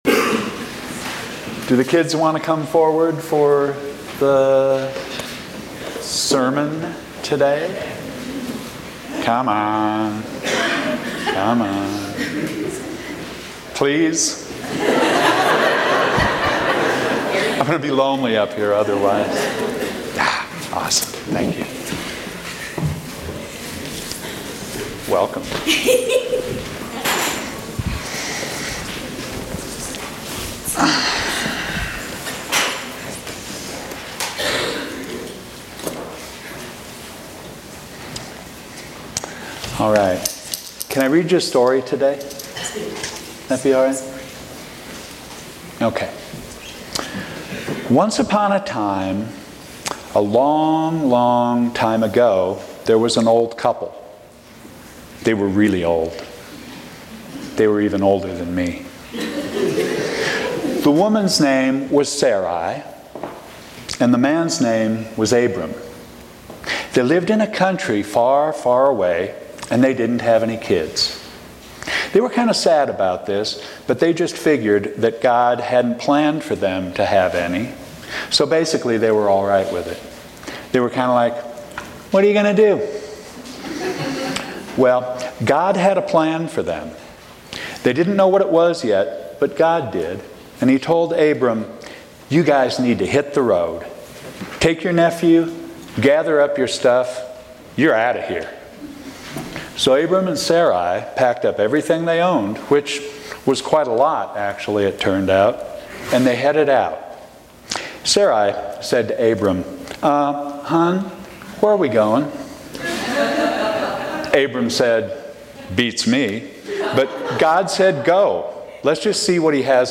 Sermons from St. John's Episcopal Church
Children's sermon. Genesis 12:1-4a; Romans 4:1-5, 13-17; John 3:1-17; Psalm 121.